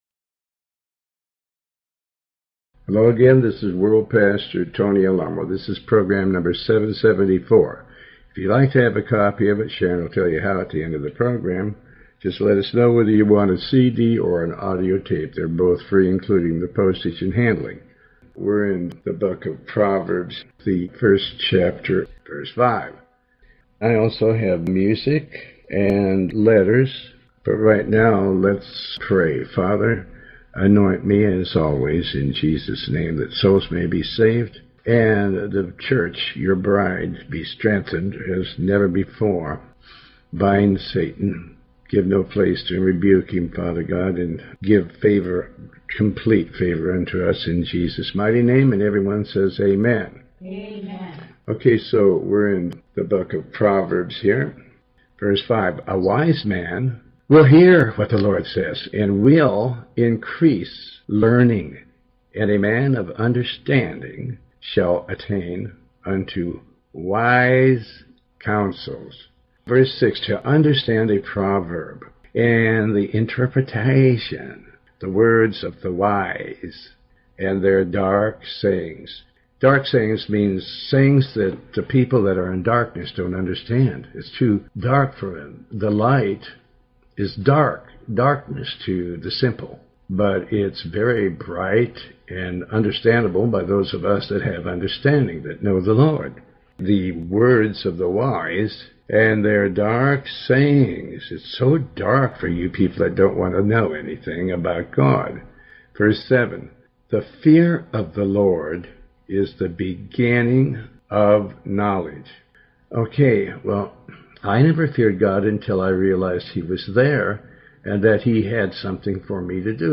In this program originally recorded in 2008, Pastor Alamo reads from and comments on Psalm 149 and Chapter 1 of the Book of Proverbs up to Chapter 2 verse 7. The program also contains letters and some music.